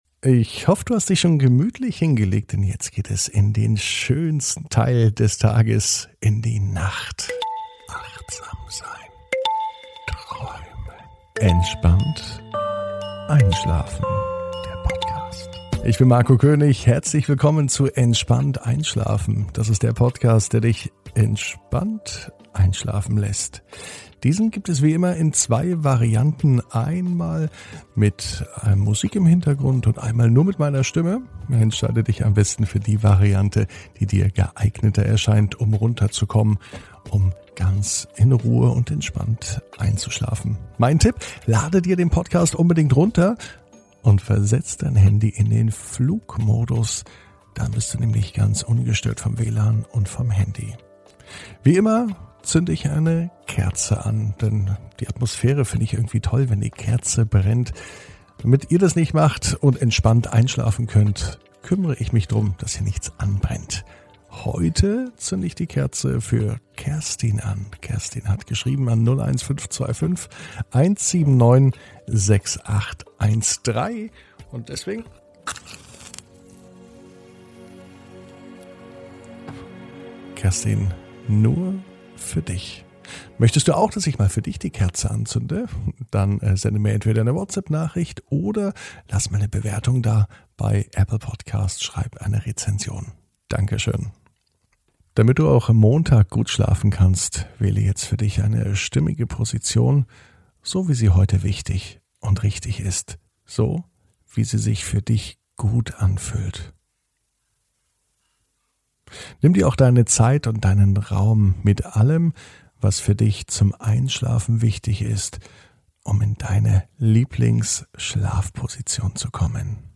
(ohne Musik) Entspannt einschlafen am Montag, 14.06.21 ~ Entspannt einschlafen - Meditation & Achtsamkeit für die Nacht Podcast